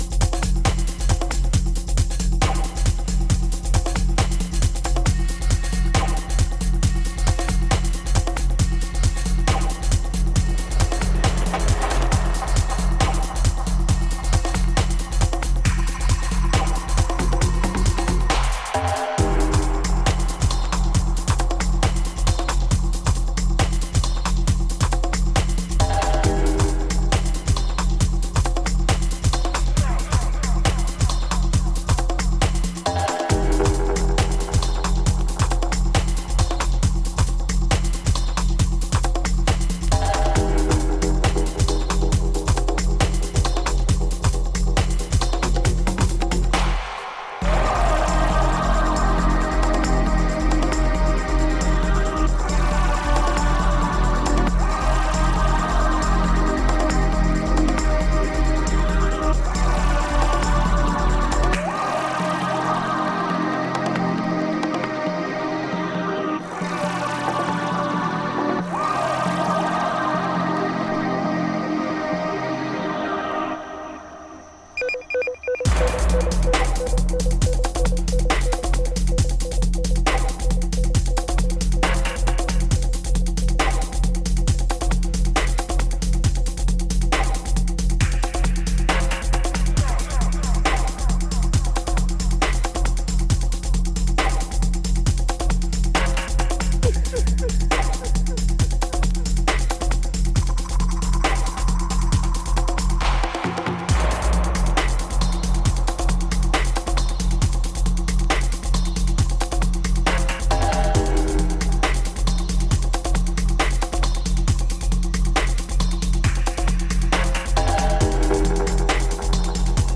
ルーツ感溢れるトライバルパーカッション、ハーフステップも織り交ぜたドラム、ぶっとくうねる重低音に痺れる136BPM